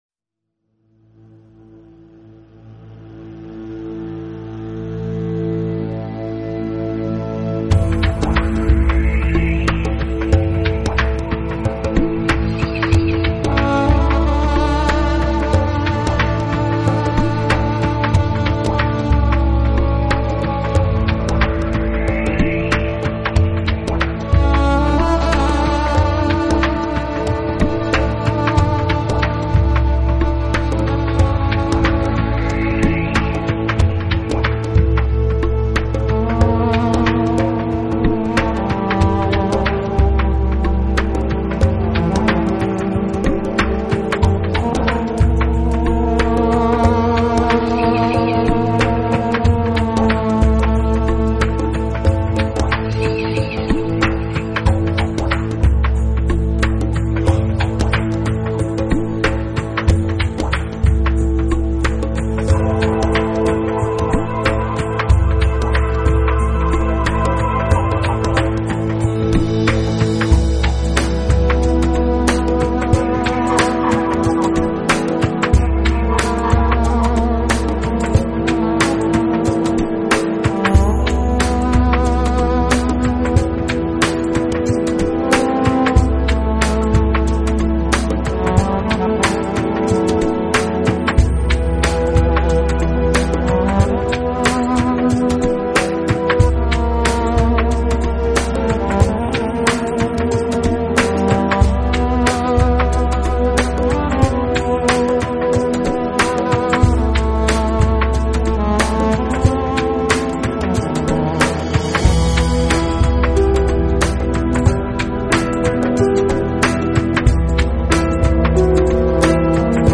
将大 提琴凝重浑厚的韵味发挥得淋漓尽致。